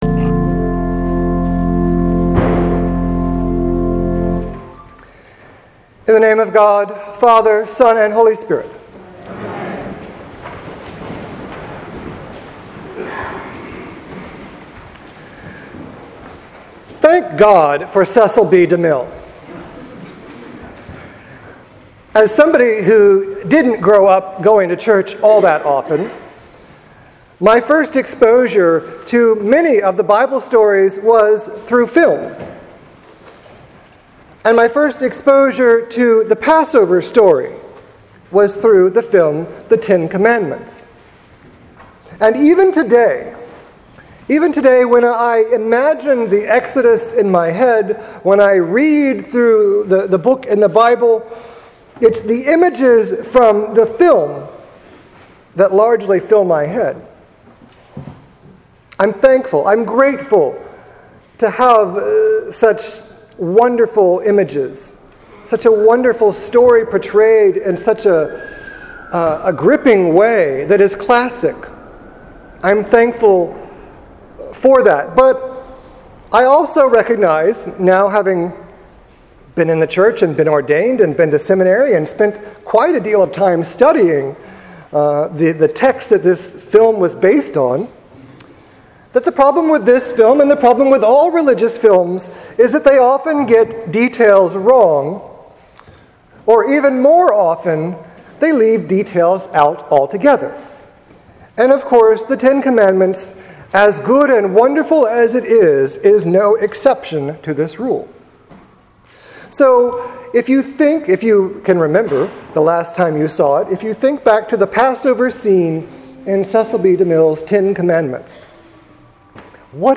maundy-thursday-2016.mp3